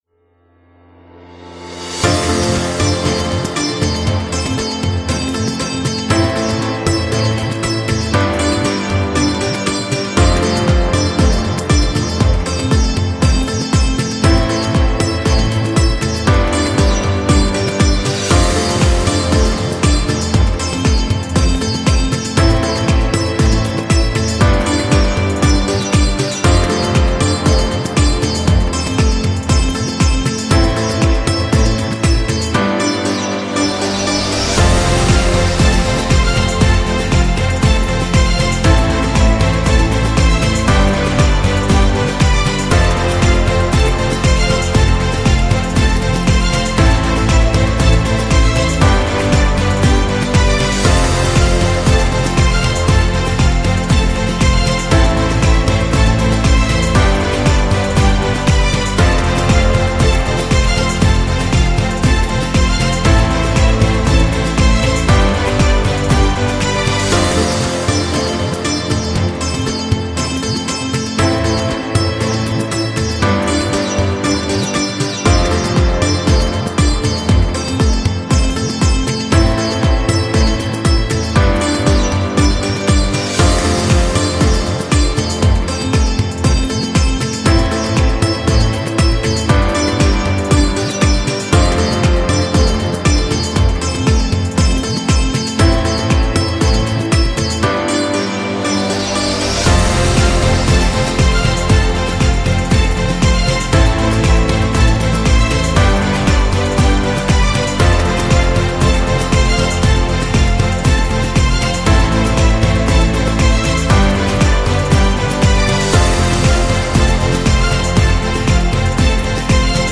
Indian Beat